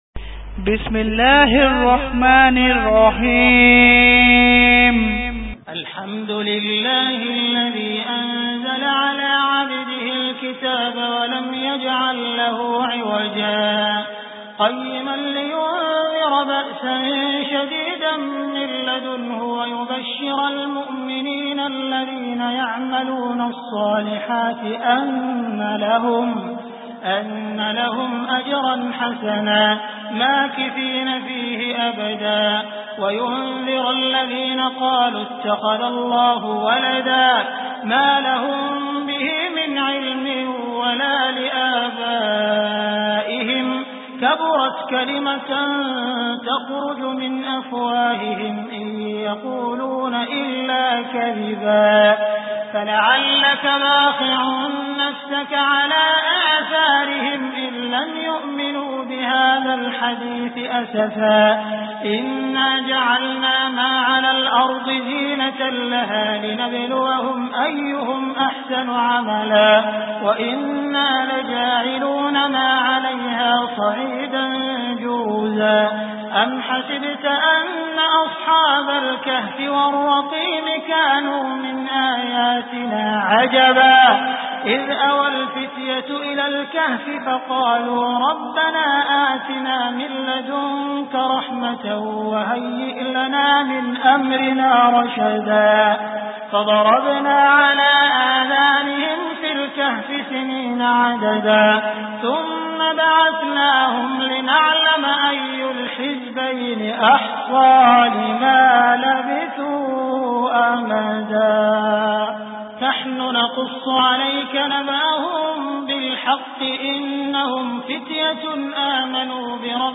Surah Al Kahf Beautiful Recitation MP3 Download By Abdul Rahman Al Sudais in best audio quality.